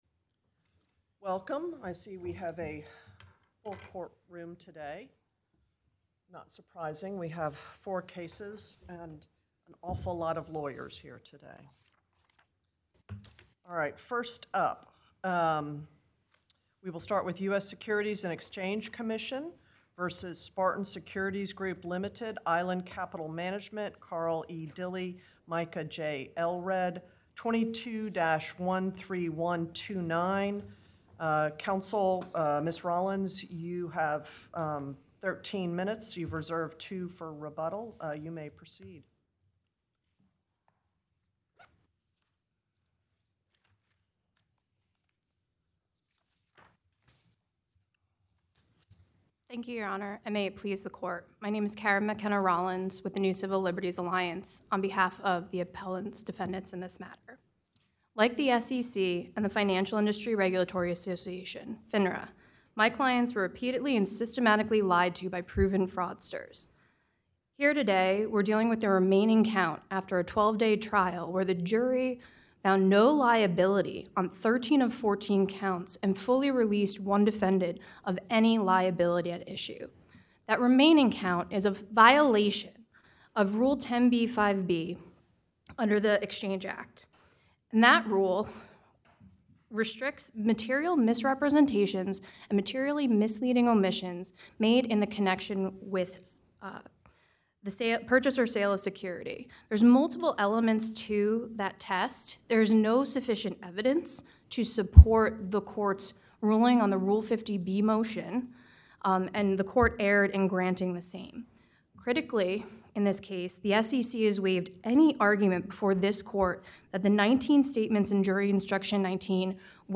Oral Argument Recordings | United States Court of Appeals